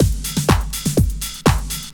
Rider Beat 2_123.wav